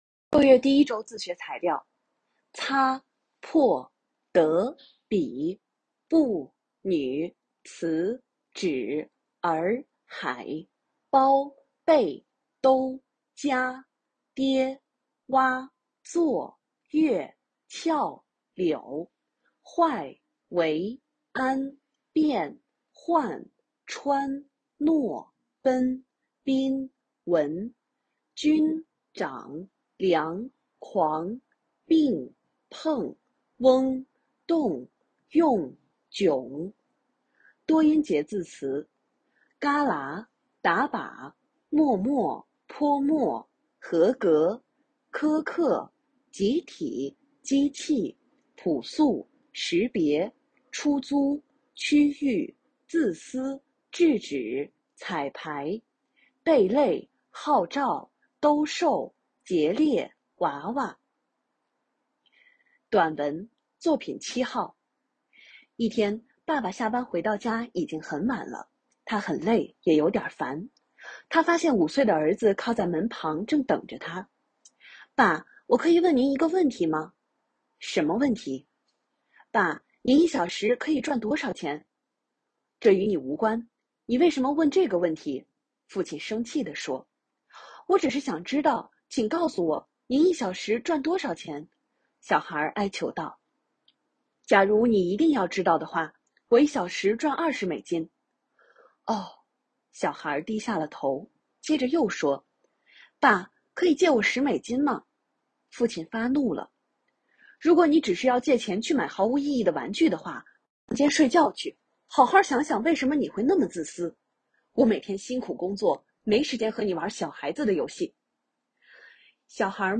领读课件